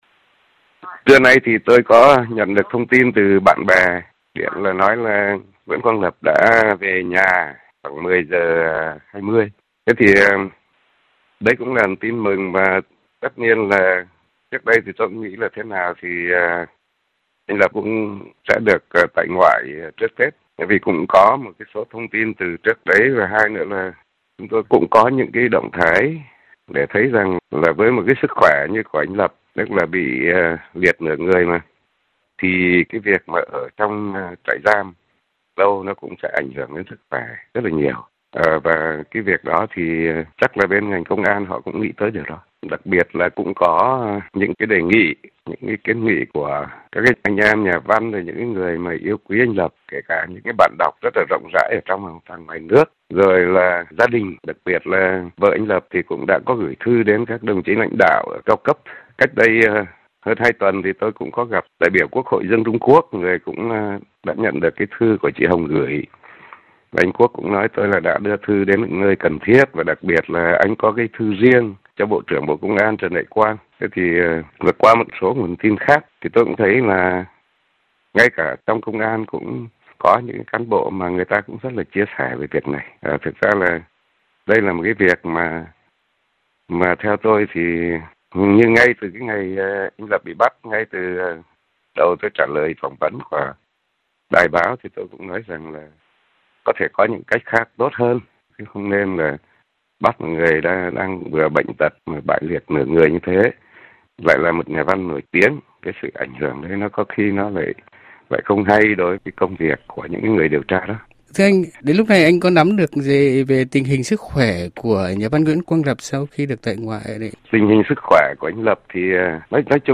Tải để nghe nhà thơ Nguyễn Trọng Tao-Hà Nội
Nhà thơ Nguyễn Trọng Tạo tại Hà Nội cho RFI biết một số cảm nhận khi nhận được tin nhà văn Nguyễn Quang Lập được tại ngoại.